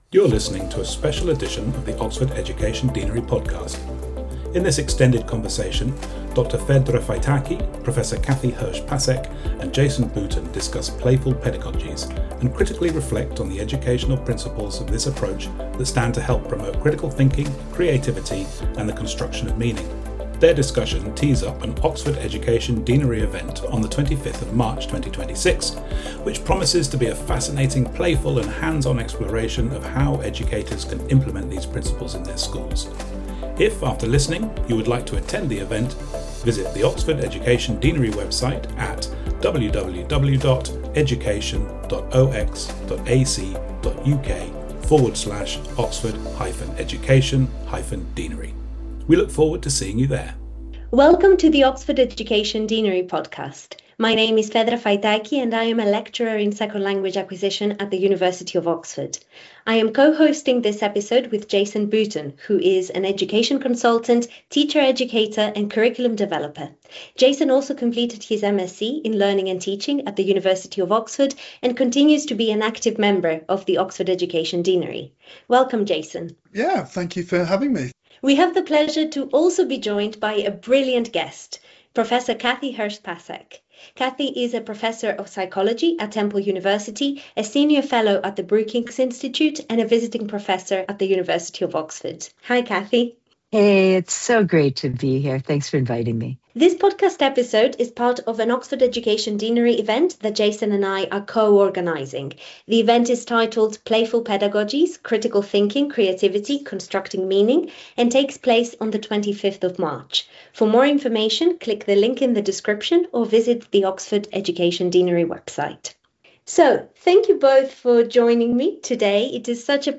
Beginning with a light-hearted exchange about climbing trees and jumping in puddles, the conversation unfolds into a compelling discussion of “active, playful learning” (or guided play) — a research-informed approach grounded in how brains learn best: actively, meaningfully, socially, iteratively, and with joy.